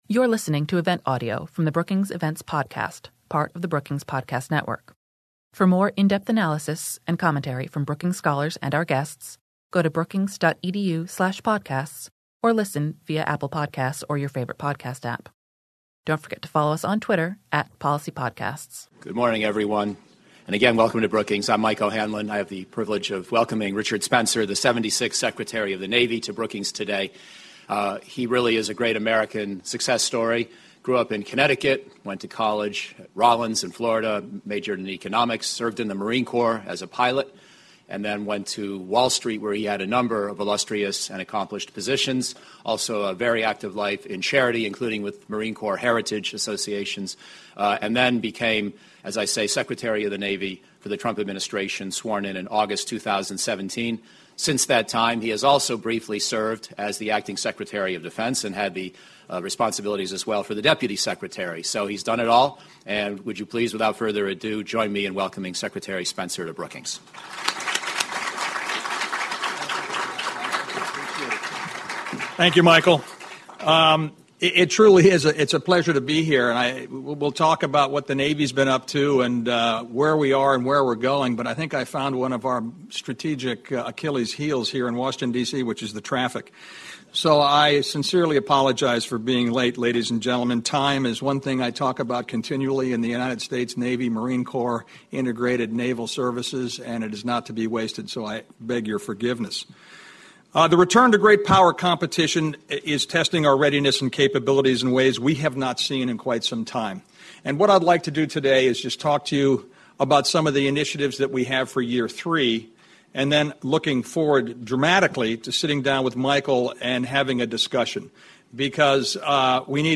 On October 23, the Brookings Institution hosted Richard Spencer, the 76th secretary of the Navy, to discuss naval modernization, the budgetary environment, and the challenges posed by America’s great power rivals to America’s maritime forces.